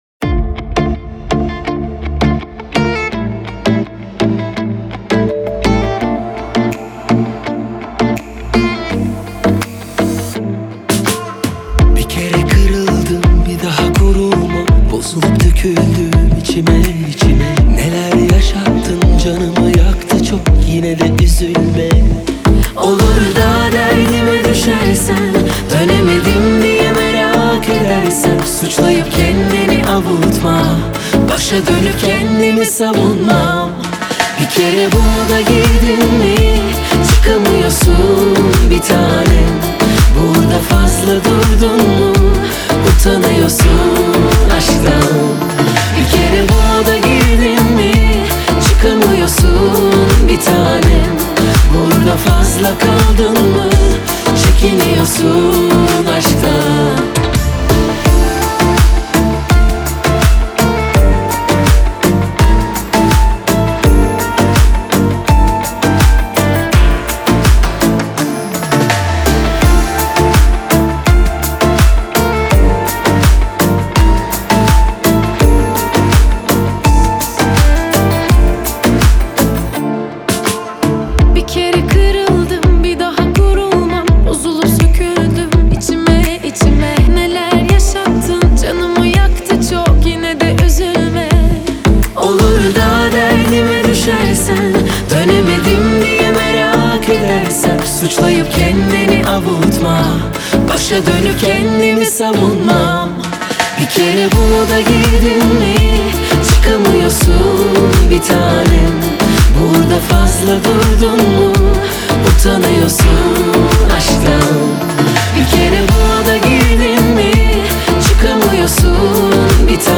آهنگ ترکی موزیک دنس